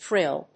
発音記号・読み方
/frɪl(米国英語)/